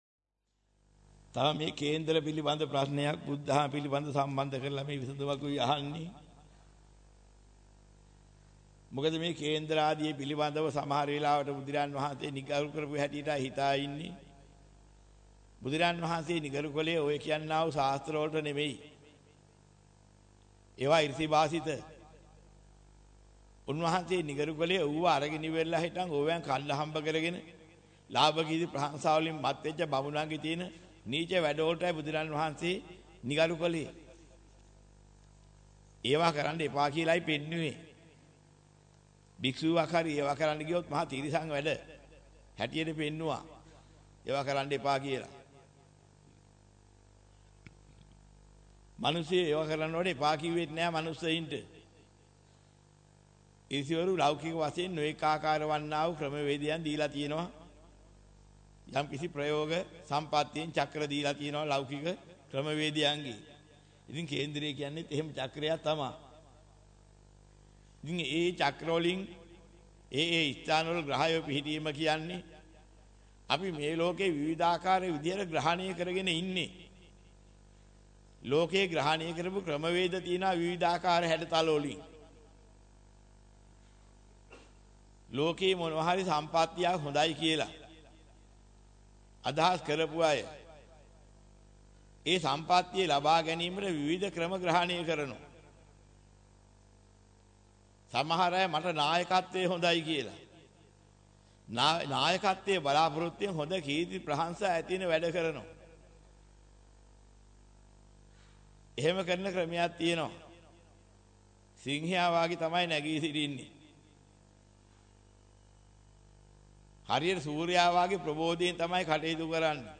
වෙනත් බ්‍රව්සරයක් භාවිතා කරන්නැයි යෝජනා කර සිටිමු 18:56 10 fast_rewind 10 fast_forward share බෙදාගන්න මෙම දේශනය පසුව සවන් දීමට අවැසි නම් මෙතැනින් බාගත කරන්න  (8 MB)